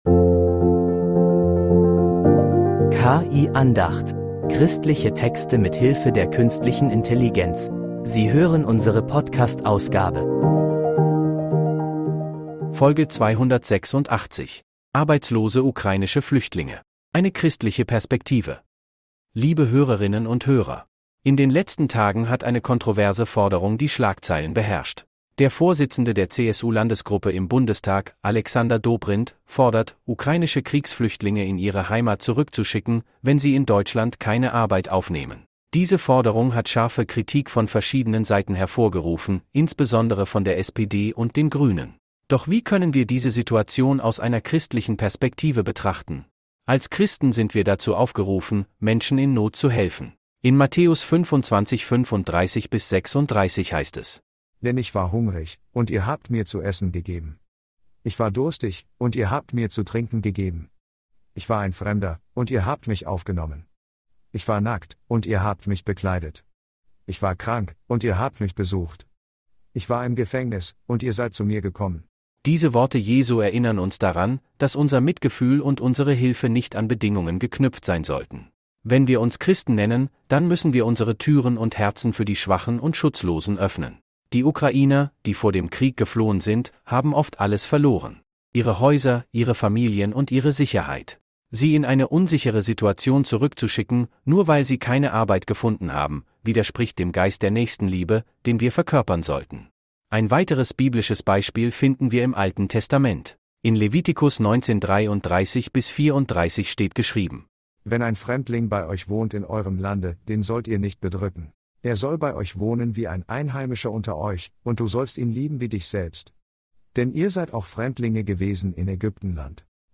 Christliche Texte mit Hilfe der Künstlichen Intelligenz
In dieser Predigt wird die Forderung der CSU, arbeitslose